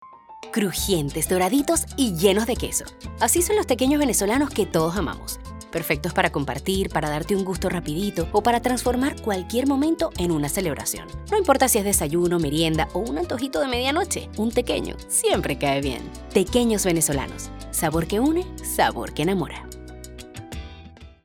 Female
Spanish (Latin American), English (Latin American Accent)
Venezuelan Accent
0209demo_venezolano.mp3